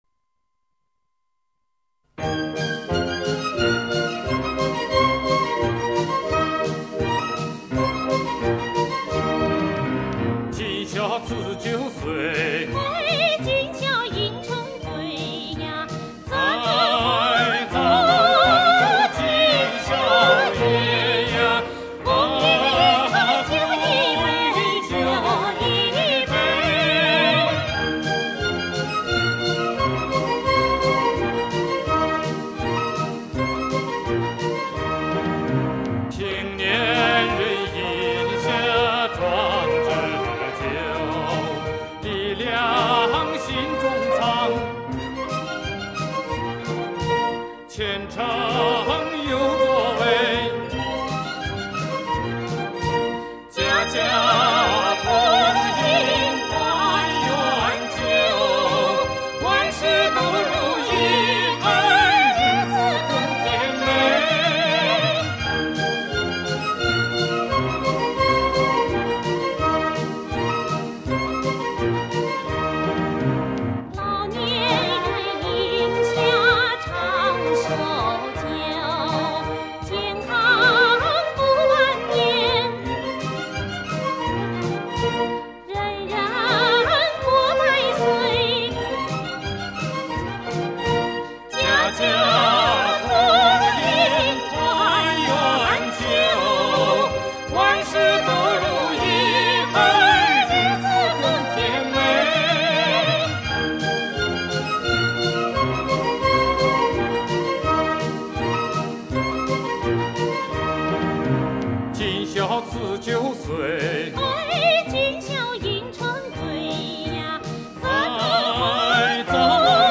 [音樂] 蒙古歌曲